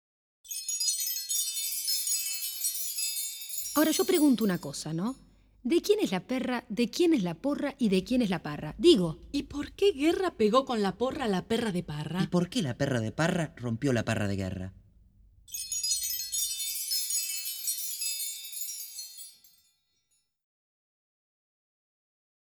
Música tradicional
Música vocal